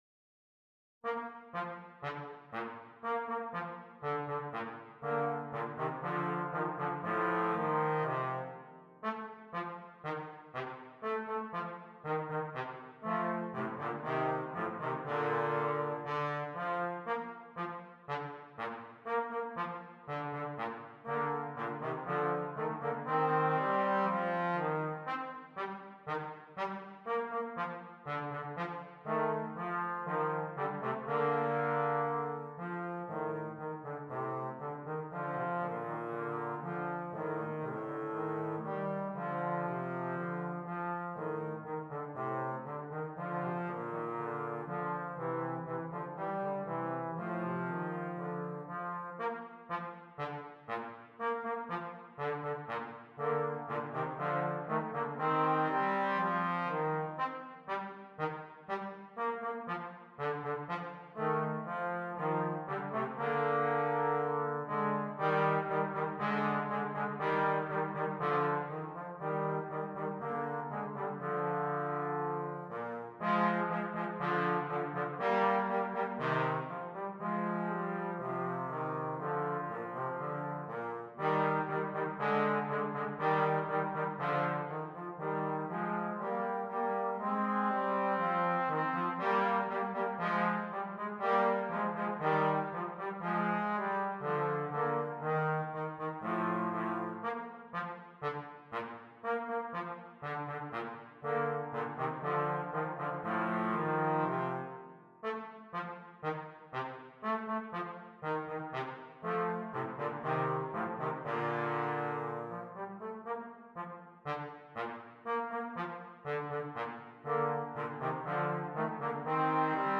Brass Band
2 Trombones